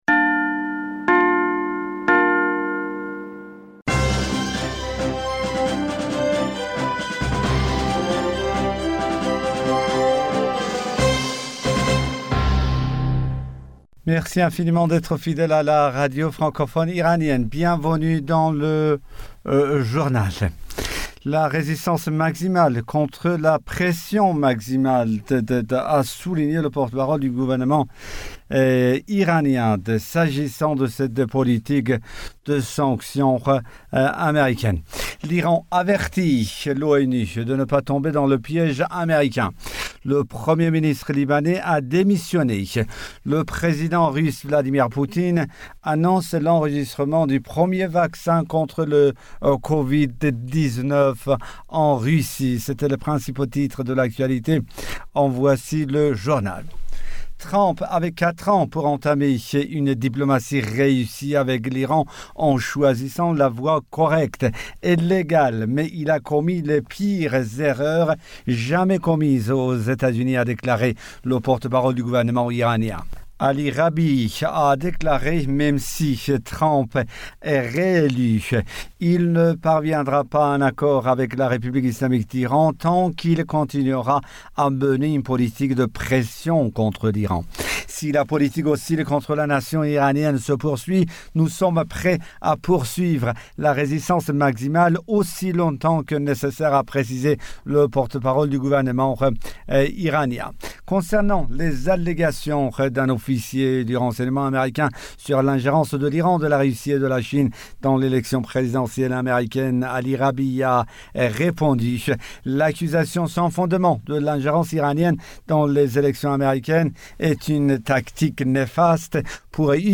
Bulletin d'information du 11 Aout 2020